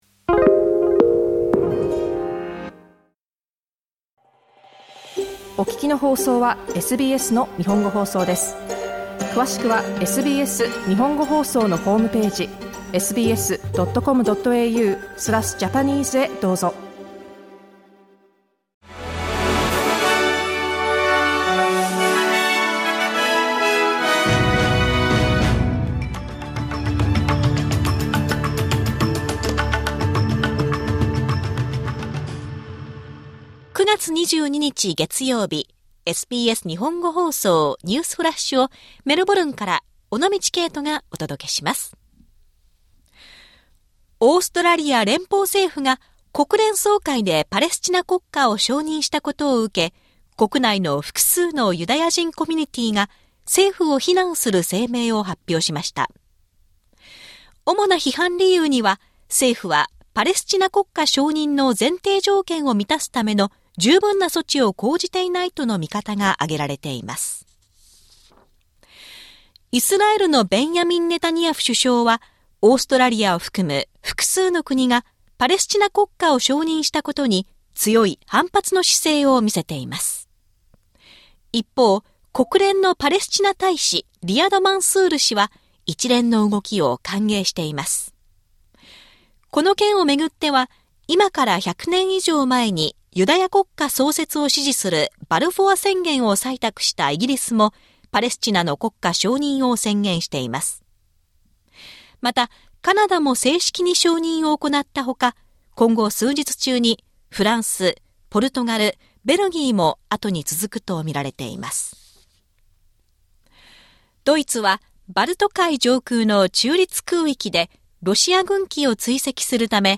SBS Japanese Newsflash Monday 22 September